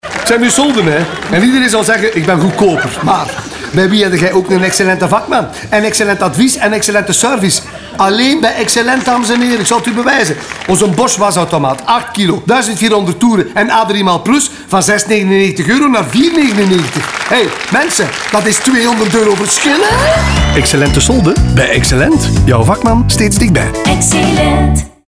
Nextage heeft voor Exellent succesvolle radiospots ontwikkeld, waarmee het zich kan onderscheiden! Een eigen stijl met een geheel aparte Tone Of Voice, essentieel om in het oor te springen!